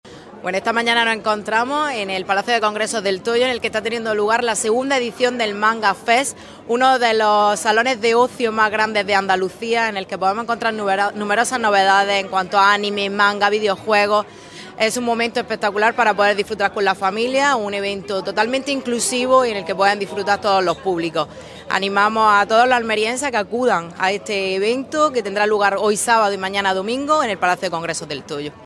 LORENA-NIETO-CONCEJALA-EMPLEO-COMERCIO-JUVENTUD-Y-EMPRENDIMIENTO.mp3